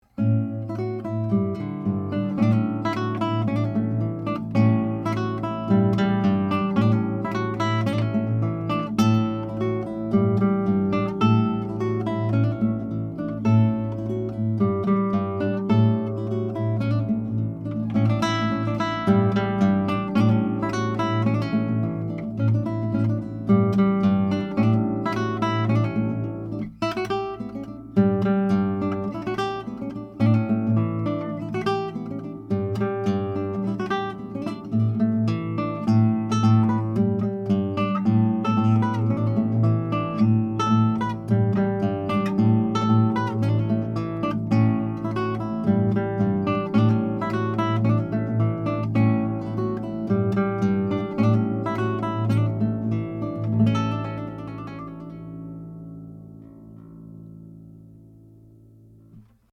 Blue Woodpecker Active Ribbon Mic, Figure 8 Pattern
Tracked through a Warm Audio WA12 into a Metric Halo ULN8 converter. No EQ, compression, effects.
CLASSICAL HARP GUITAR